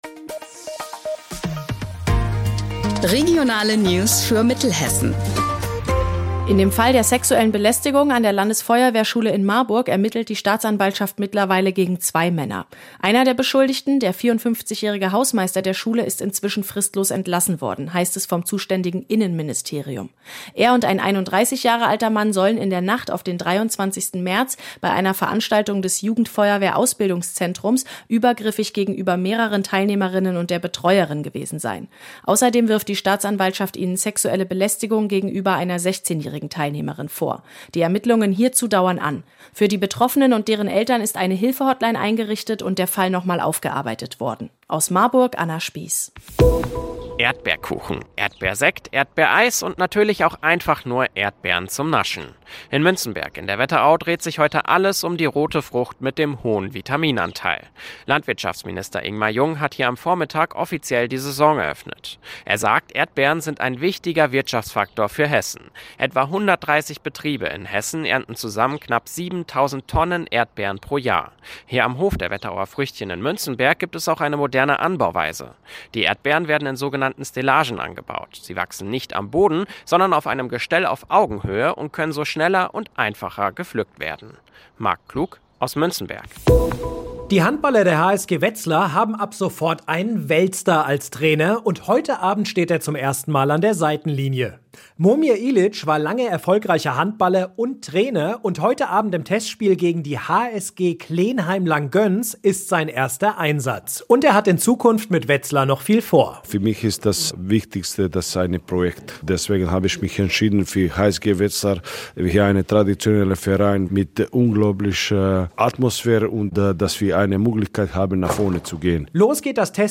Mittags eine aktuelle Reportage des Studios Gießen für die Region.